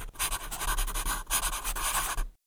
WritingSound.wav